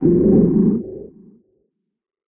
PixelPerfectionCE/assets/minecraft/sounds/mob/horse/zombie/idle1.ogg at bbd1d0b0bb63cc90fbf0aa243f1a45be154b59b4